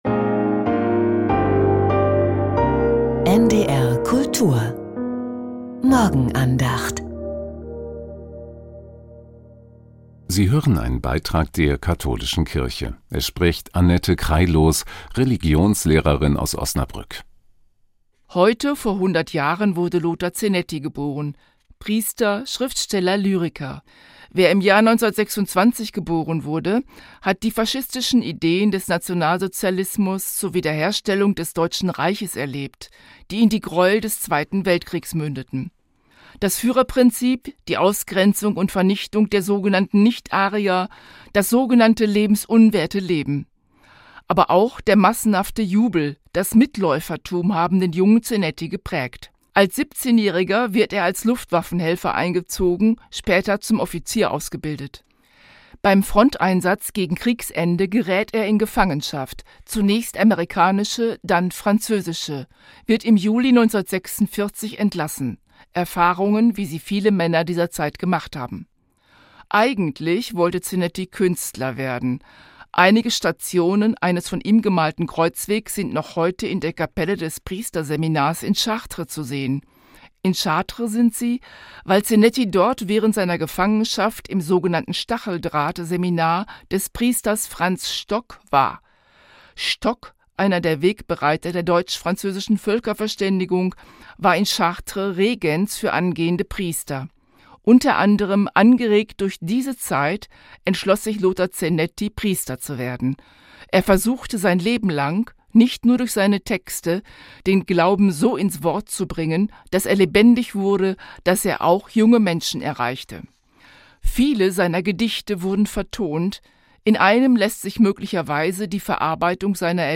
Morgenandacht